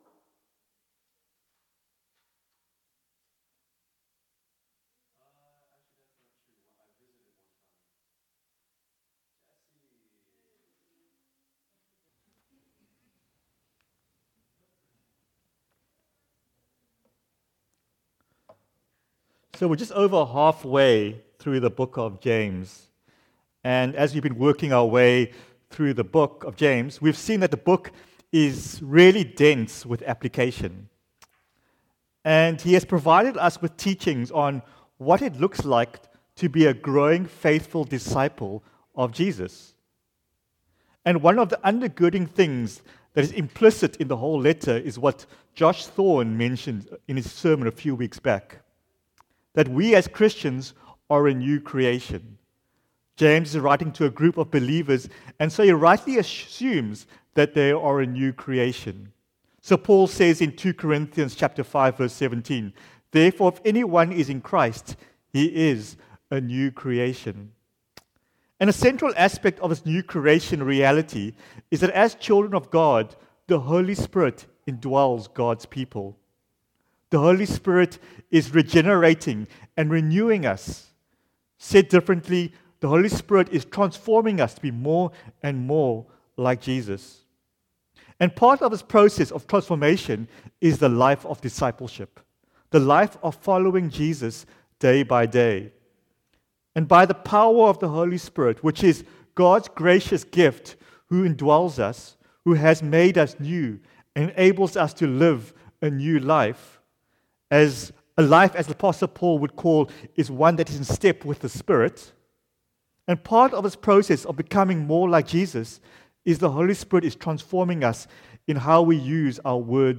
JSAC Sermons
JSAC_March_8_Sunday_Morning.mp3